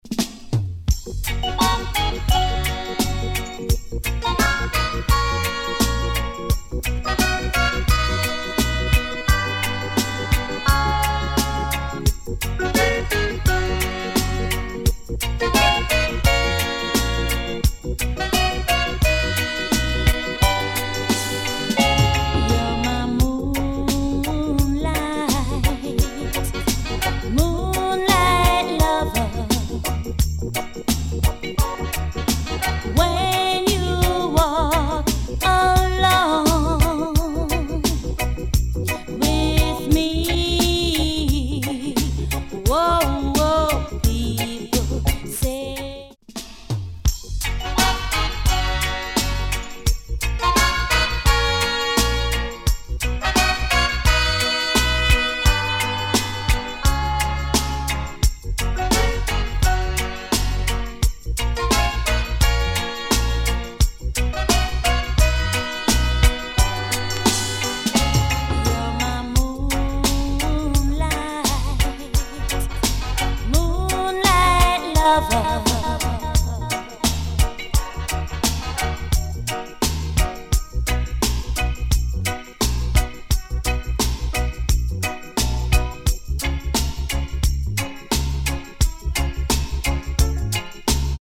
We record our sound files with no EQ is added.